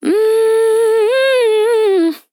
Categories: Vocals Tags: dry, english, female, fill, LOFI VIBES, MMMM, sample